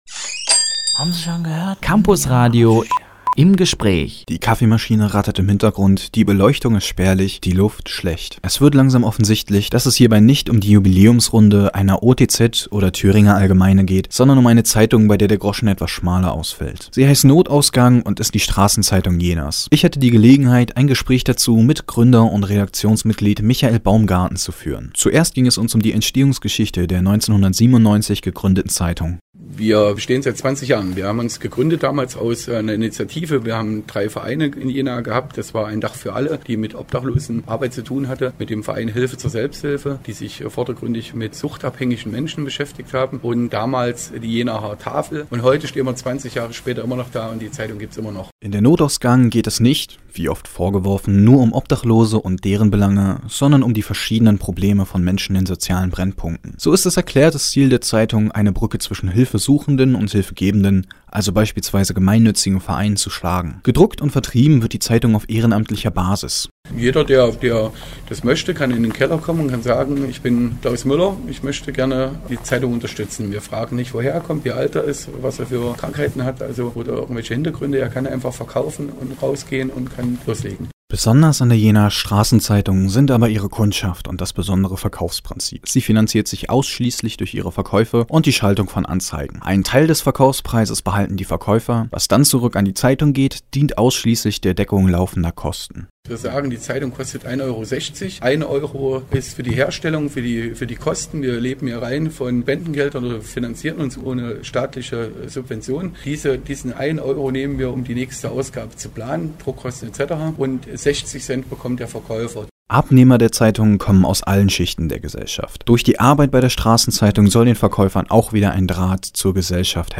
Im Gespräch – 20 Jahre Notausgang – Campusradio Jena